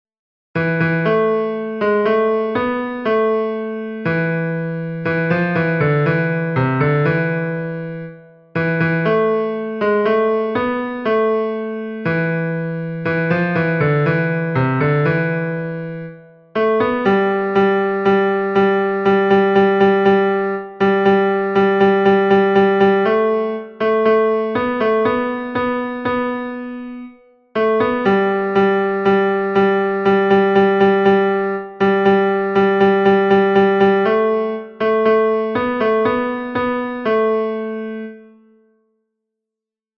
Barytons